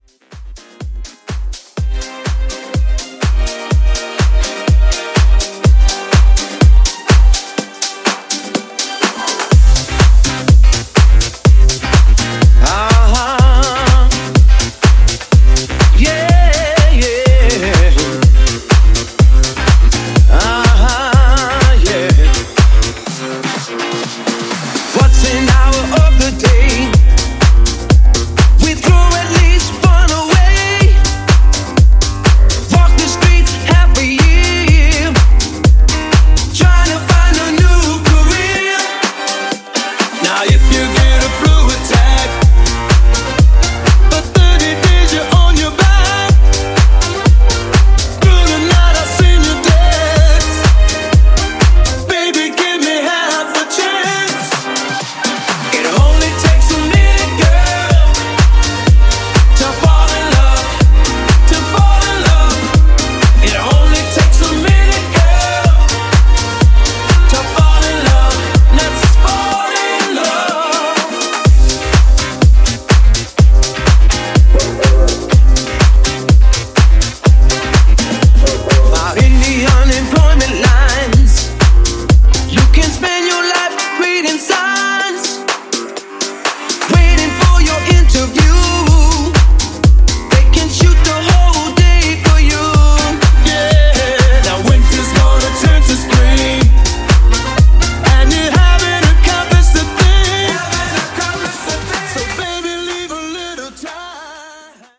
Genre: HIPHOP
Clean BPM: 100 Time